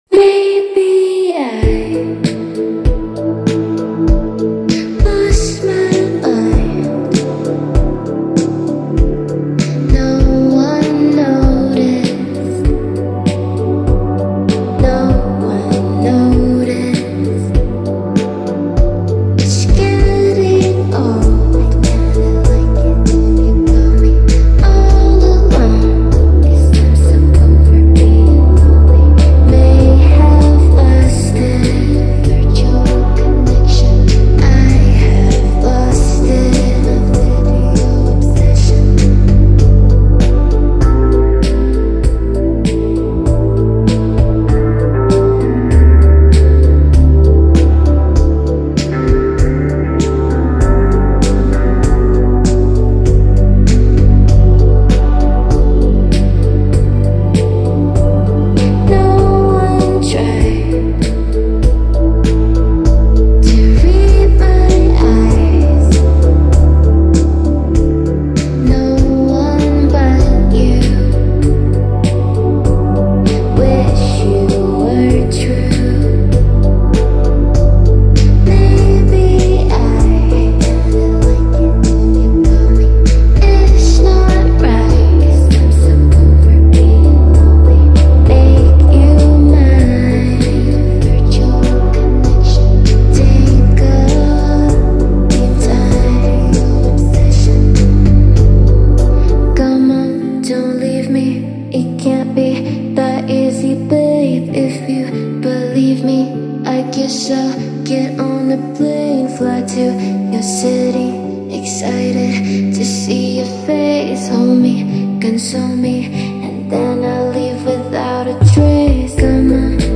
погружает слушателя в атмосферу меланхолии и introspection